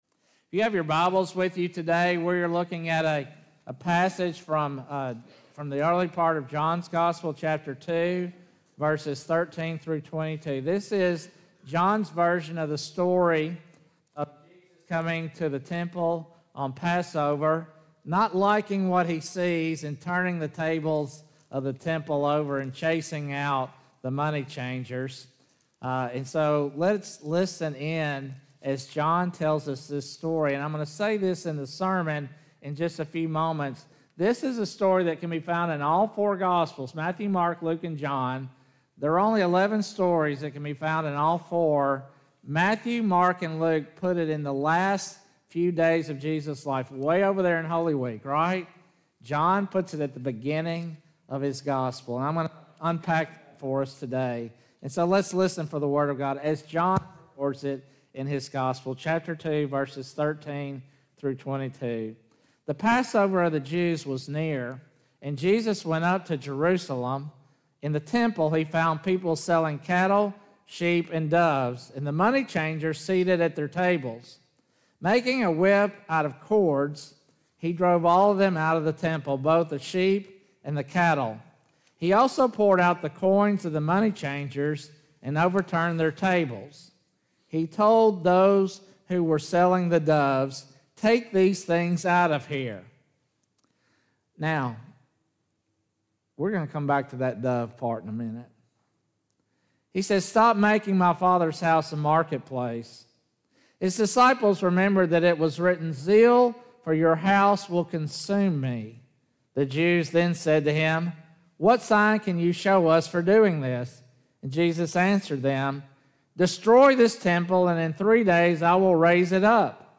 Sermons | First United Methodist Church